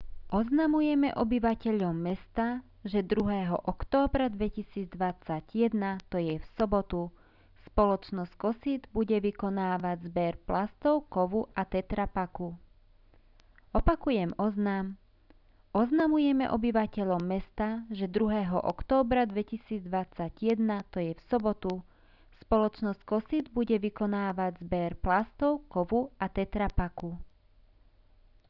Hlásenia v mestskom rozhlase